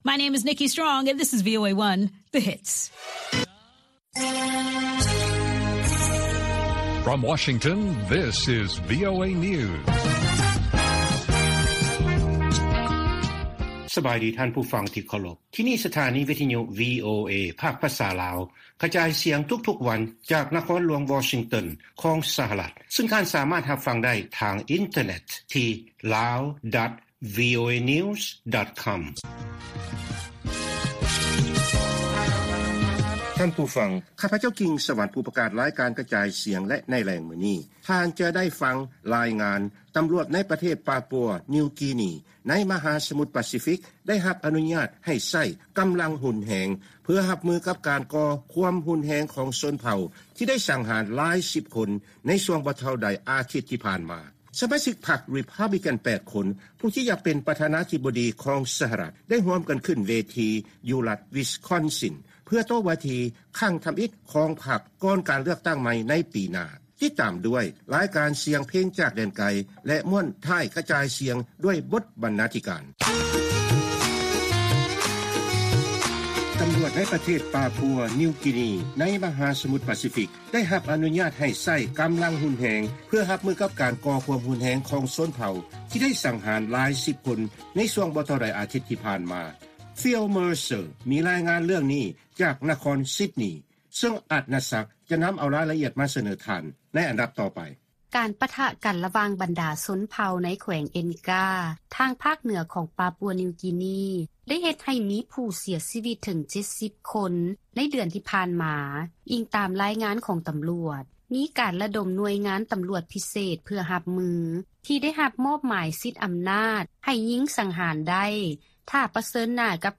ລາຍການກະຈາຍສຽງຂອງວີໂອເອ ລາວ: ການກໍ່ຄວາມຮຸນແຮງຂອງຊົນເຜົ່າ ໄດ້ສັງຫານຫຼາຍສິບຄົນ ໃນ ປາປົວ ນິວ ກີນີ